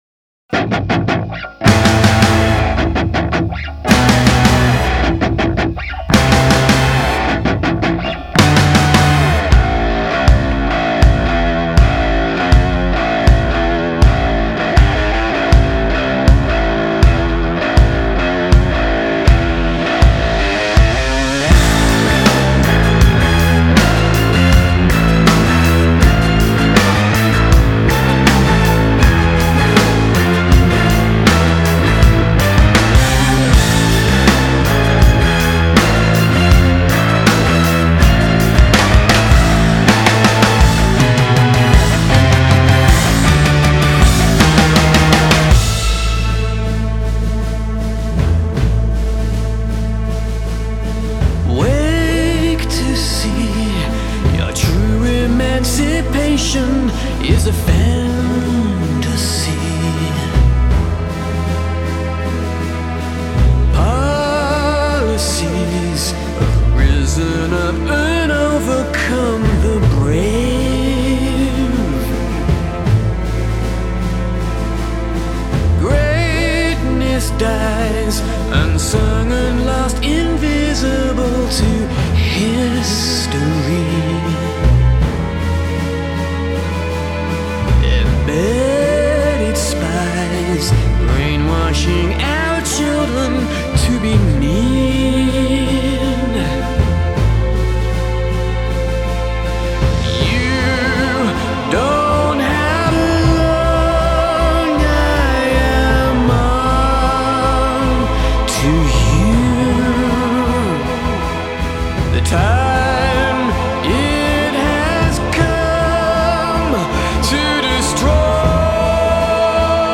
Альтернативный рок